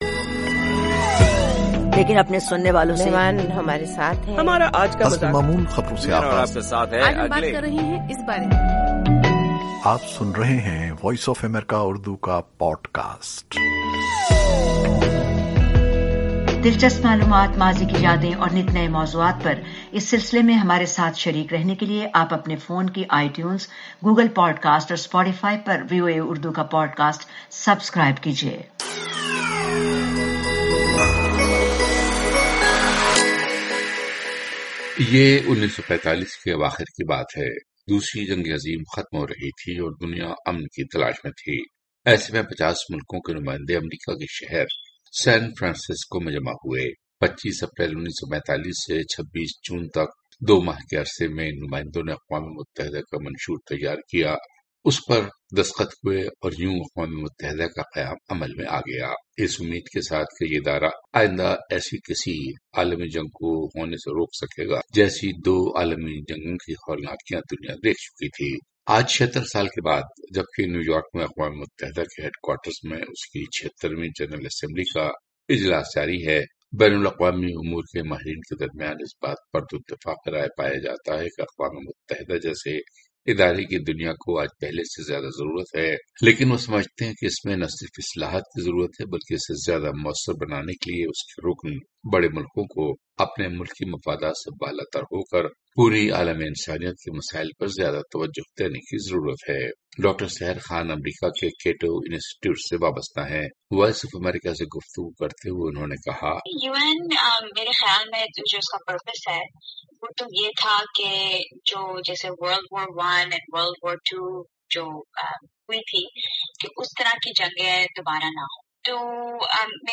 گفتگو۔